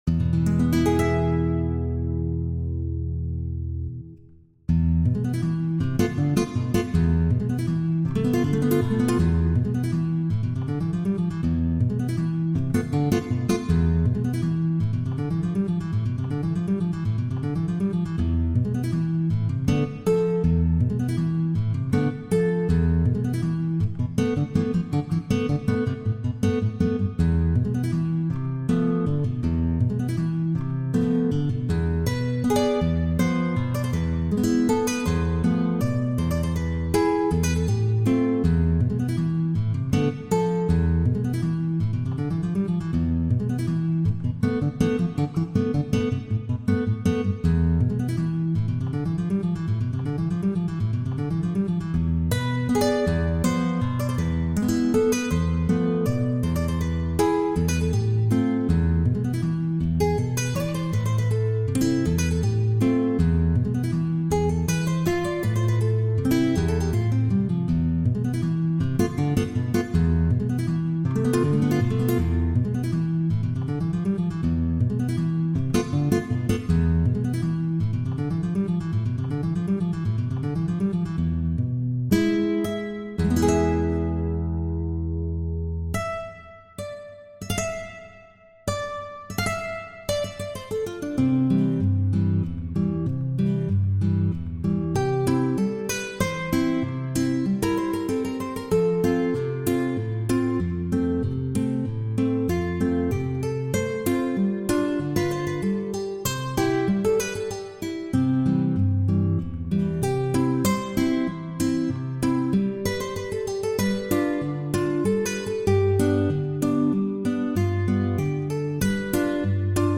Ses oeuvres gardent cette force puissante et poétique du caractère ibérique même interprétées à la guitare.